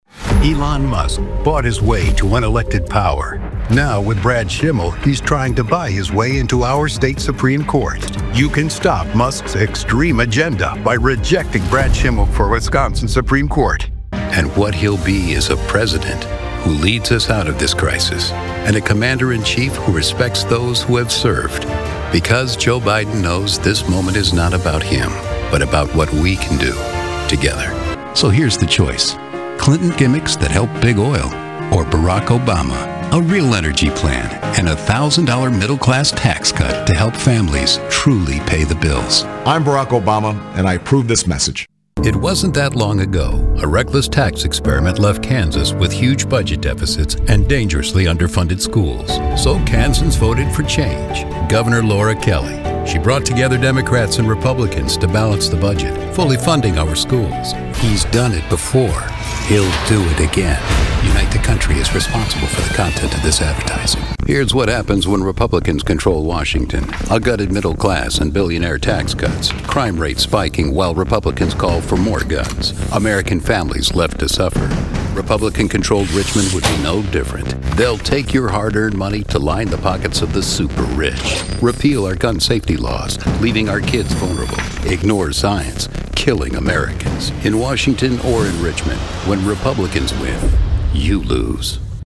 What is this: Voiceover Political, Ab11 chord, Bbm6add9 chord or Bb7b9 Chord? Voiceover Political